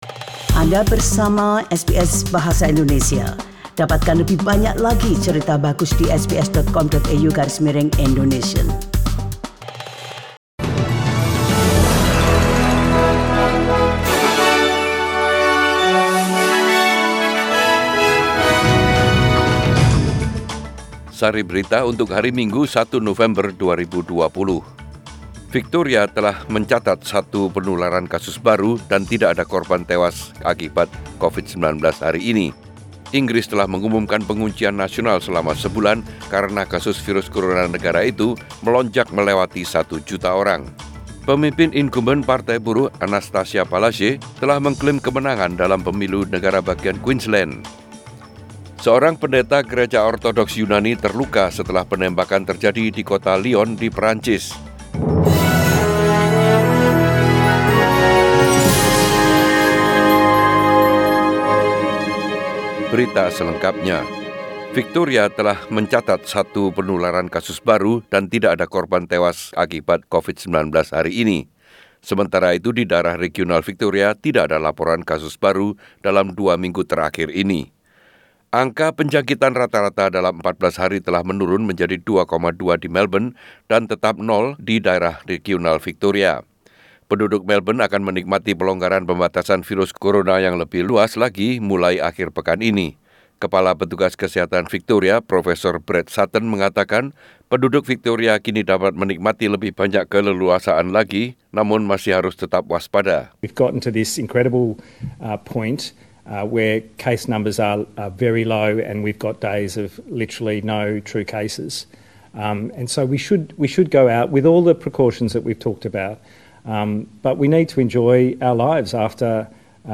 Warta Berita Radio SBS Program Bahasa Indonesia Source: SBS